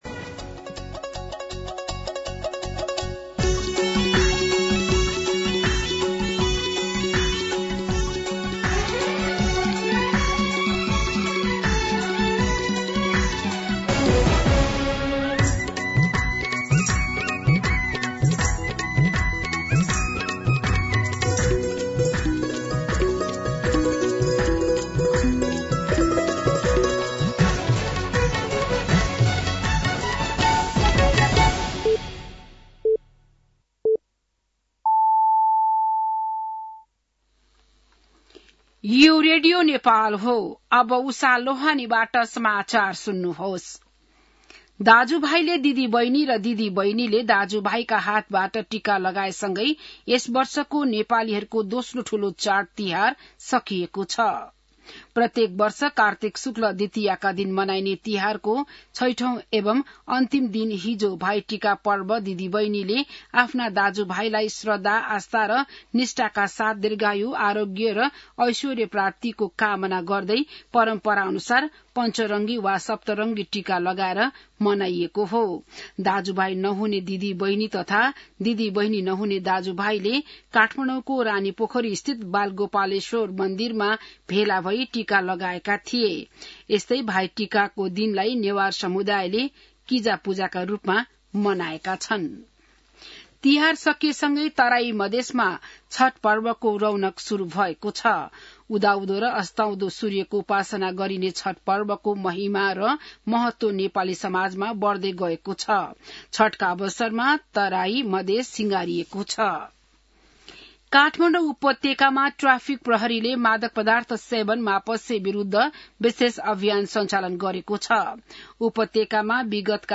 बिहान ११ बजेको नेपाली समाचार : ७ कार्तिक , २०८२
11-am-Nepali-News-8.mp3